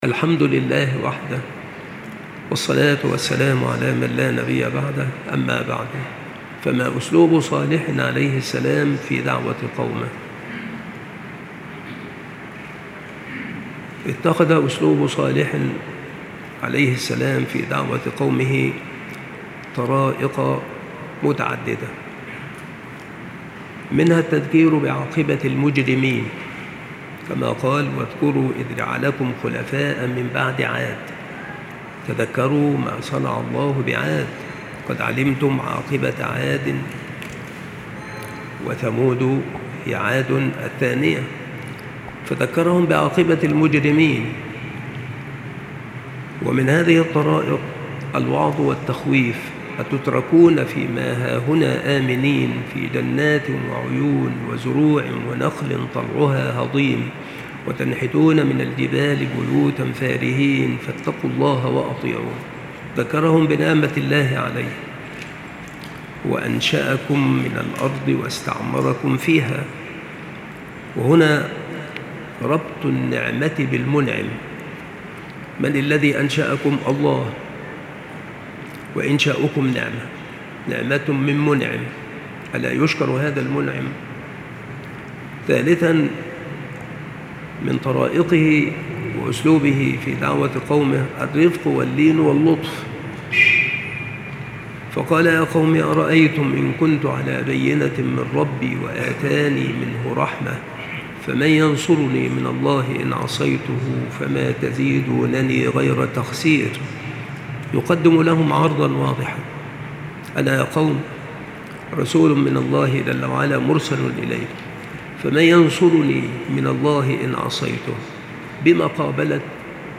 التصنيف : قصص الأنبياء
• مكان إلقاء هذه المحاضرة : بالمسجد الشرقي - سبك الأحد - أشمون - محافظة المنوفية - مصر